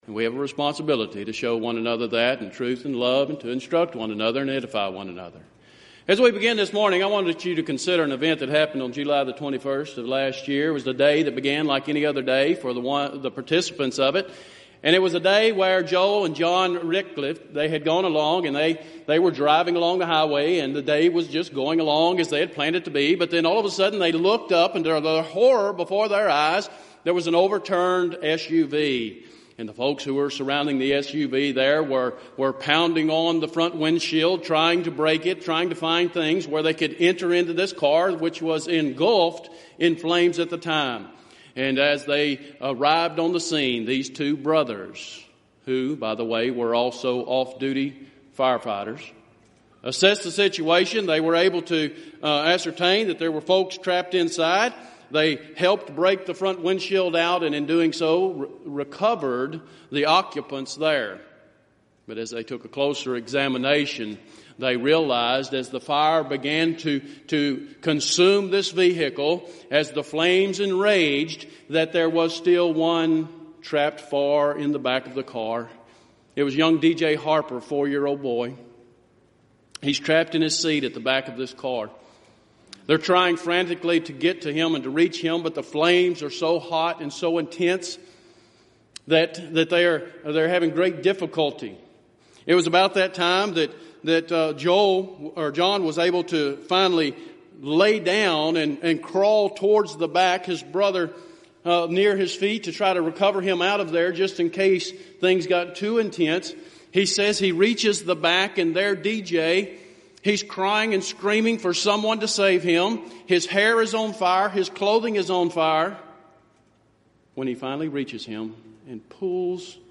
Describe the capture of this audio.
Event: 29th Annual Southwest Lectures Theme/Title: Proclaiming Christ: Called Unto Salvation